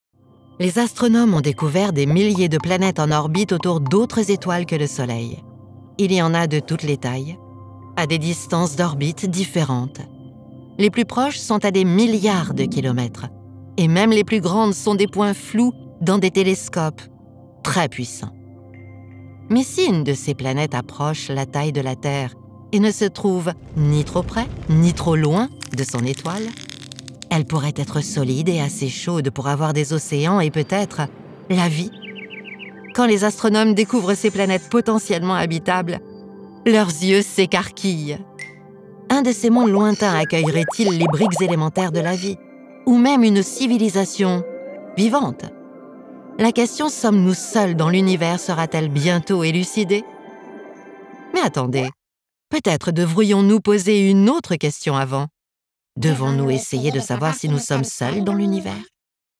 Female
French (Parisienne)
Adult (30-50)
Documentary